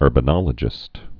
(ûrbə-nŏlə-jĭst)